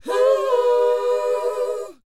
WHOA F#B.wav